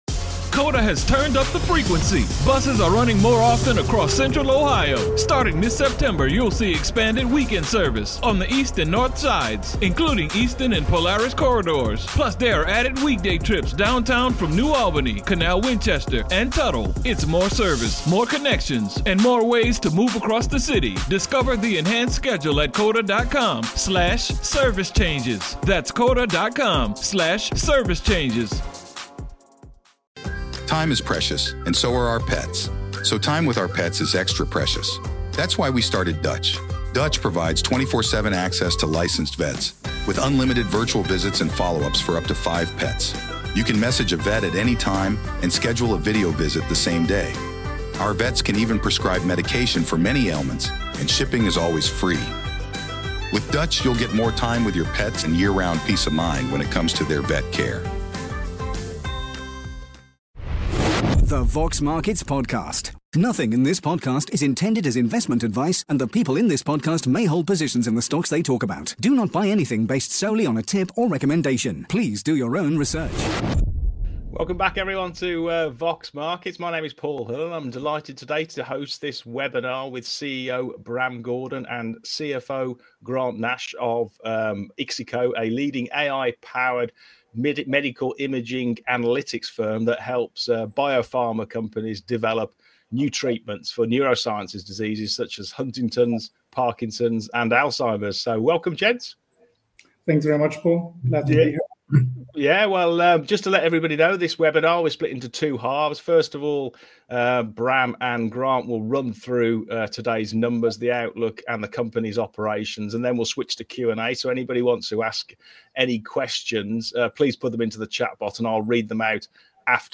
IXICO Full Year Results & Growth Strategy Webinar